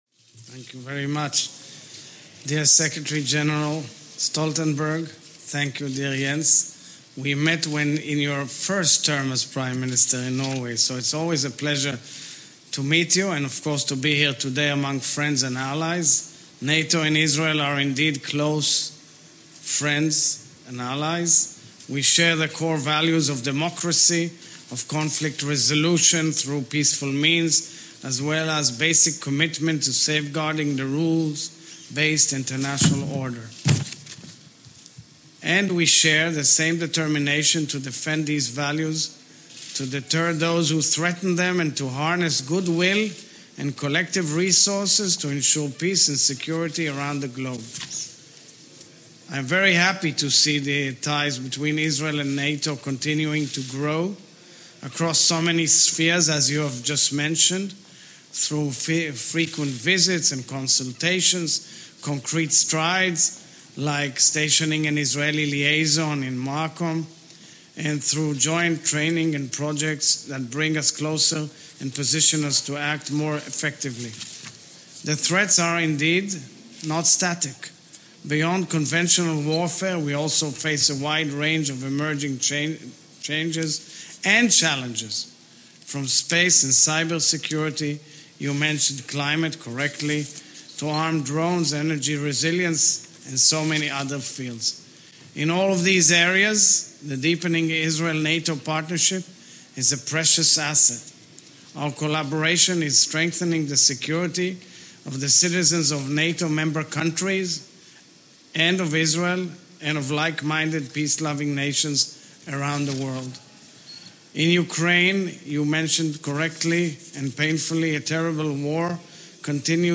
President Isaac Herzog
Press Statement at NATO Headquarters
delivered 26 January 2023, Brussels, Belgium
Audio Note: AR-XE = American Rhetoric Extreme Enhancement